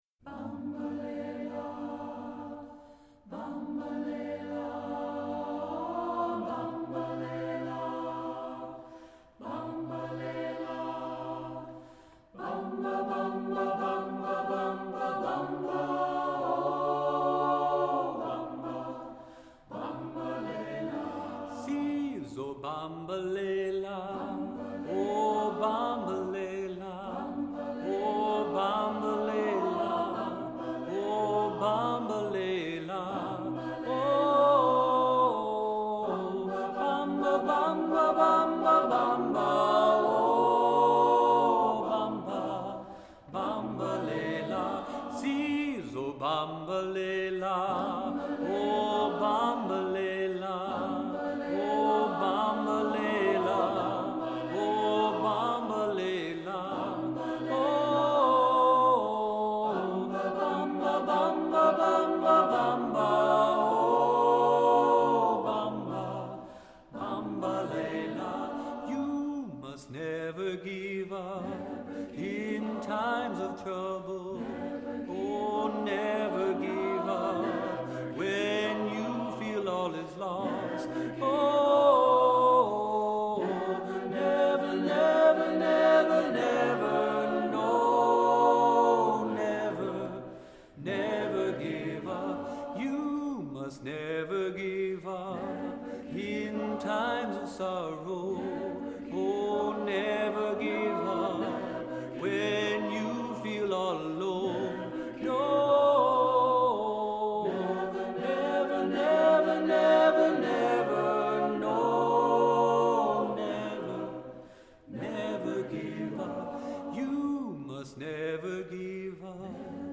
Accompaniment:      Reduction
Music Category:      Christian